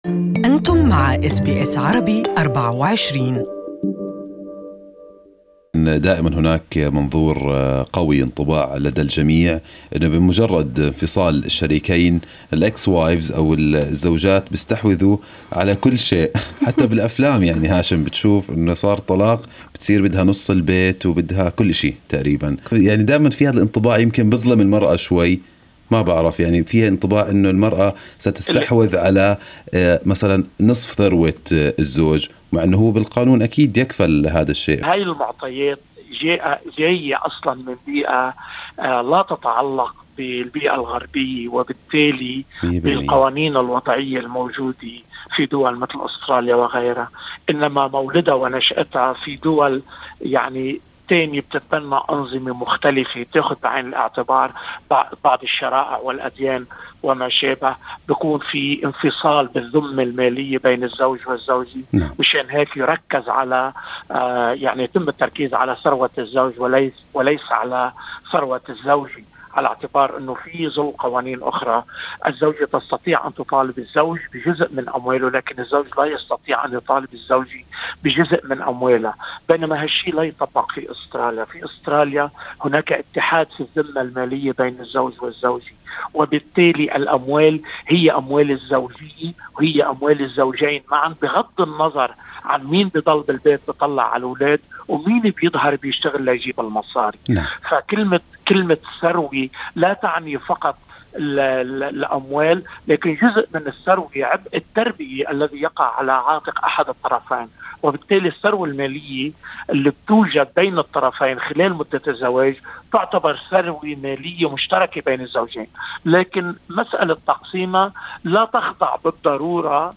في حديث لبرنامج Good Morning Australia